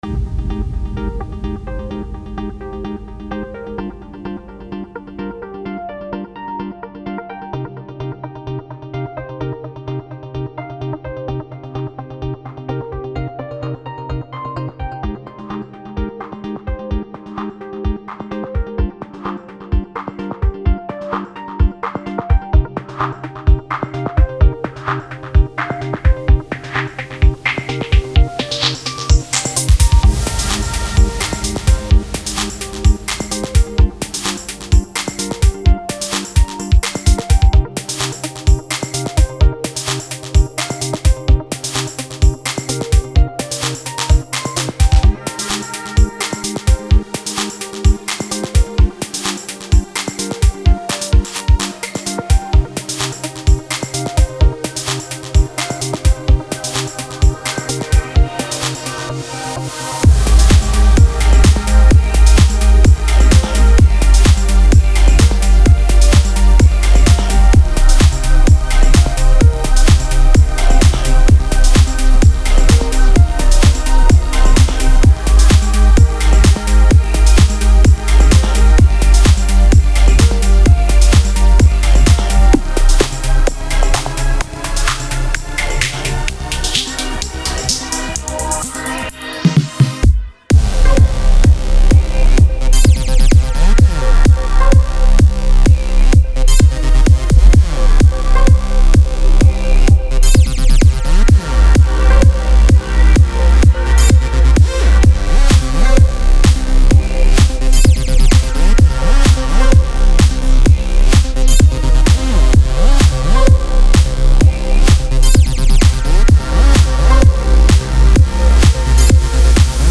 Electro House